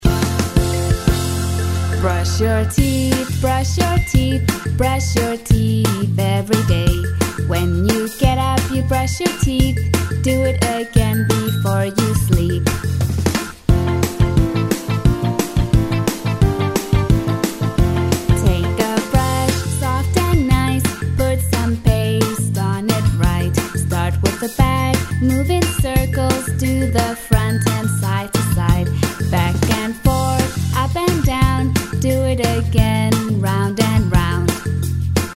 美式英语 女声 唱歌
积极向上|时尚活力|亲切甜美